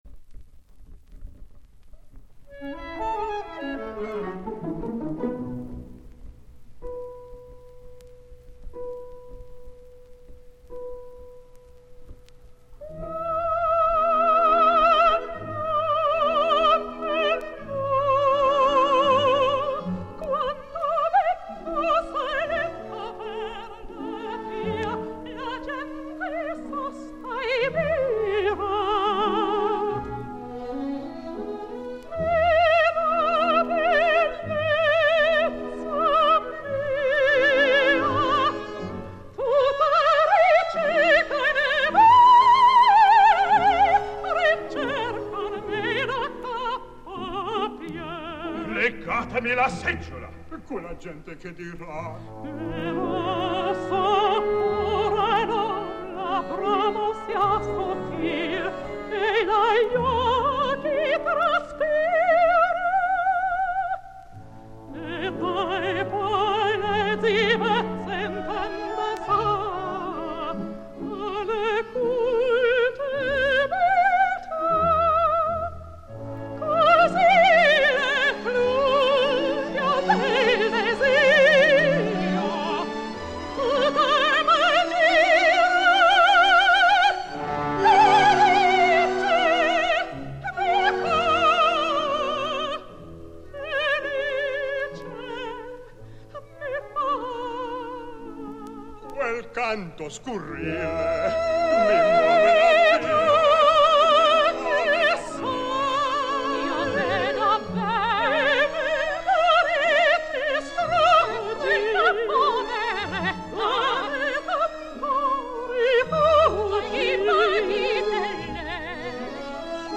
la marevellosa soprano
tenor
bajo